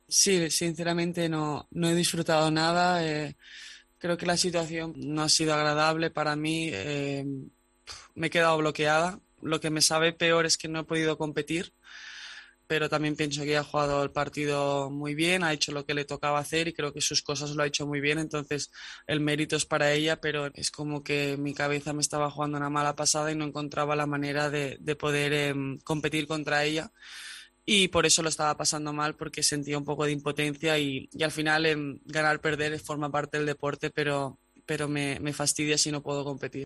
AUDIO: La tenista española intentó explicar la dura derrota ante Muguruza en las semifinales del Masters femenino, que le hizo llorar durante el partido.